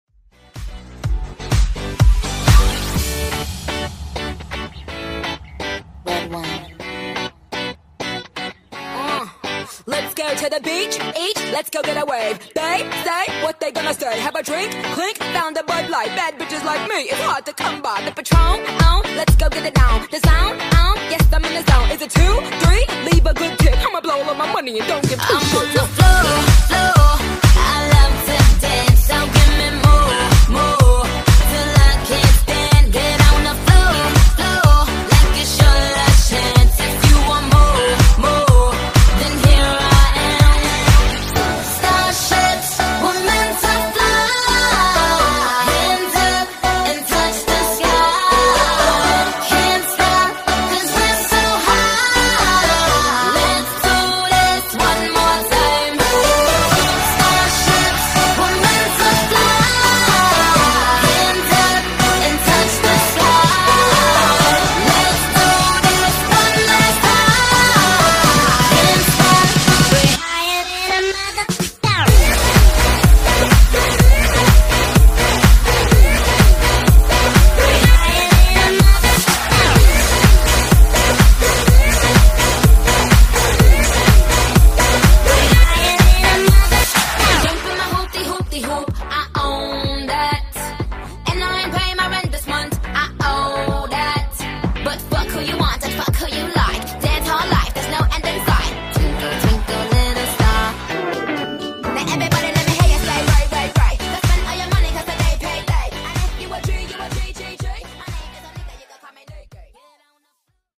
Genres: DANCE , RE-DRUM Version: Dirty BPM: 125 Time